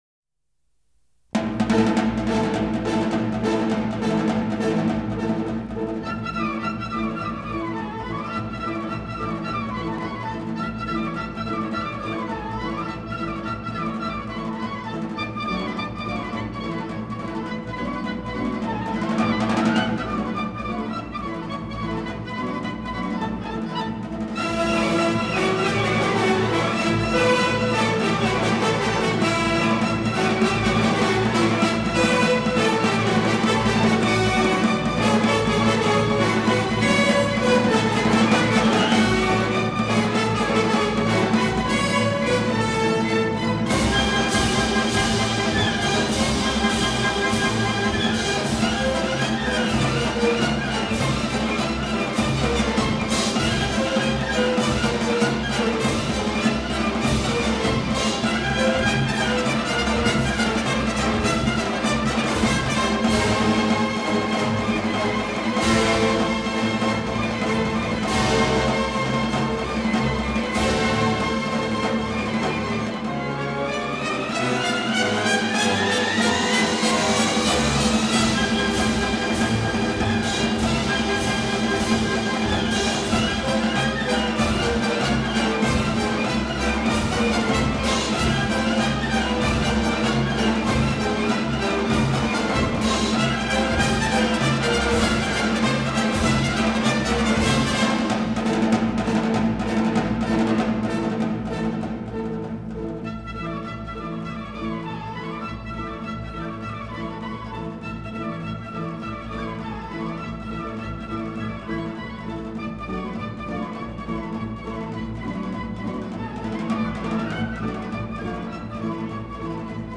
Лезгинка